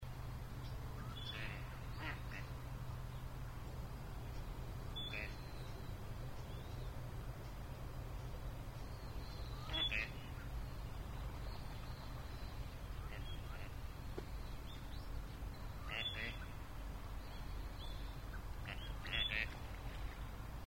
26okayoshigamo_nakigoe.mp3